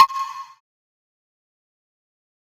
MagicCity Perc 14.wav